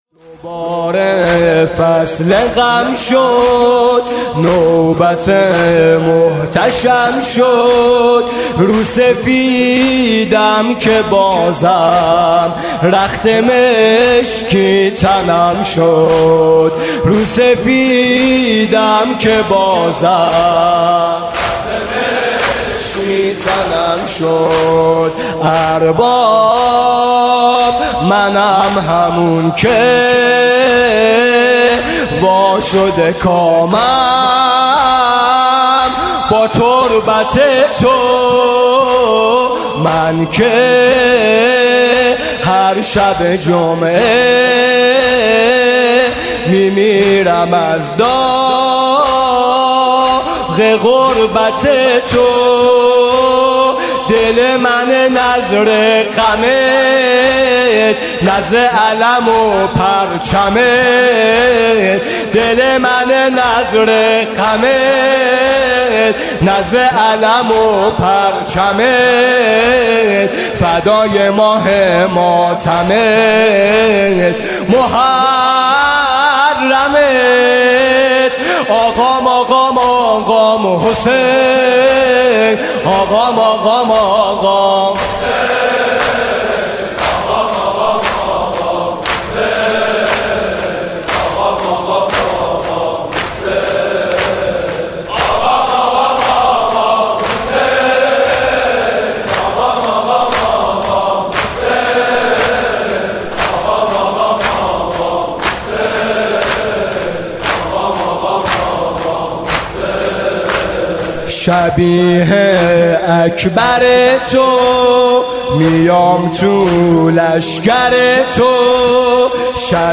محرم 95